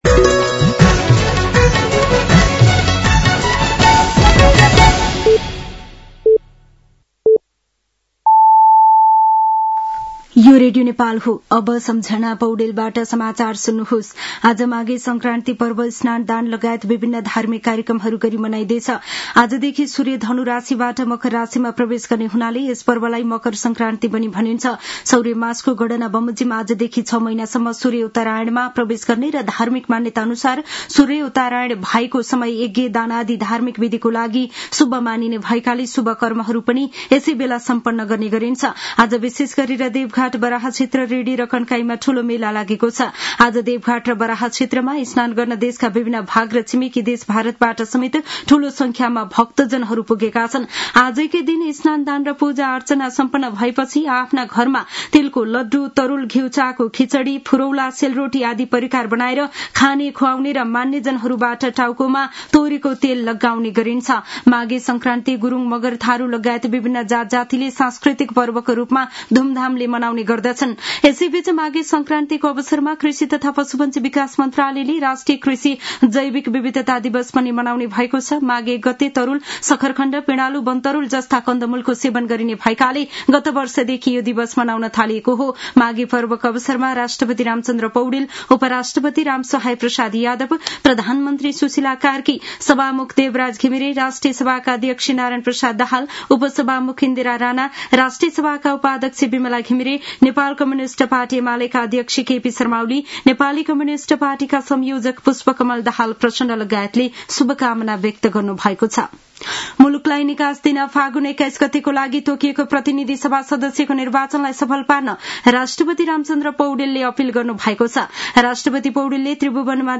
साँझ ५ बजेको नेपाली समाचार : १ माघ , २०८२